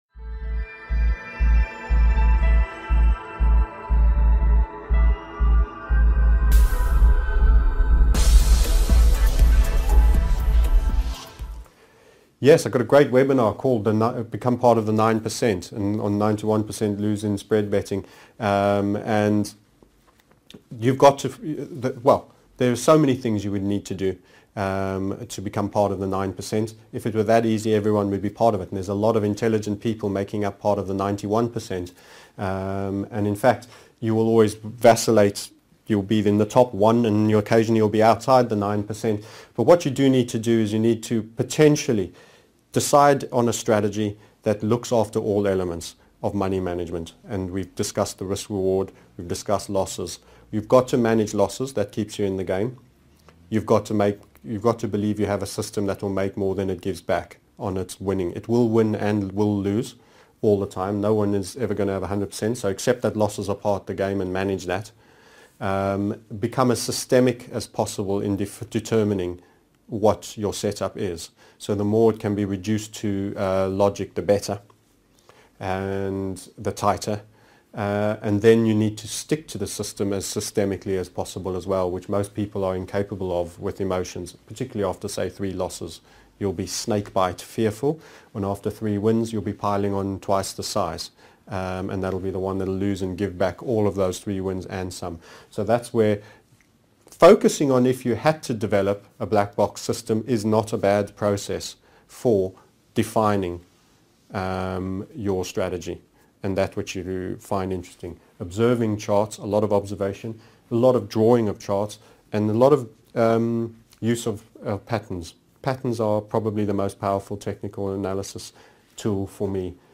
19 What are the bad habits to avoid TMS Interviewed Series 19 of 32